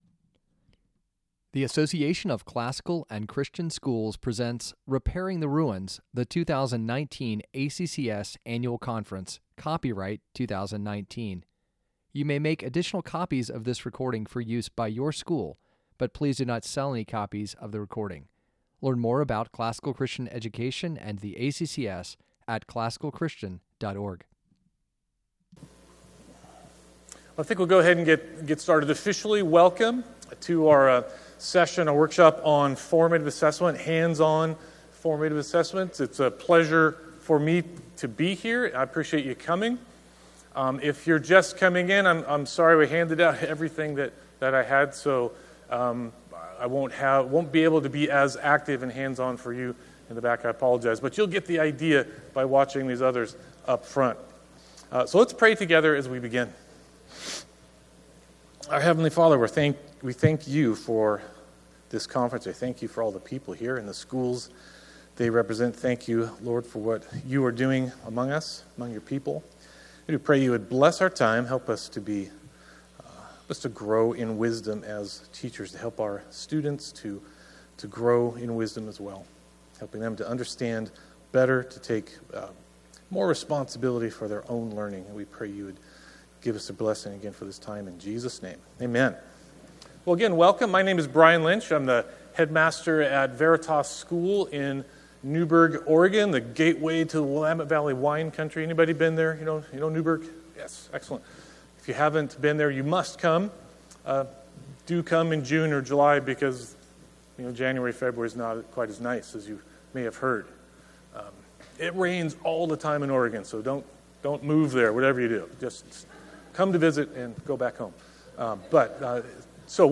2019 Foundations Talk | 59:42 | 7-12, Teacher & Classroom
The Association of Classical & Christian Schools presents Repairing the Ruins, the ACCS annual conference, copyright ACCS.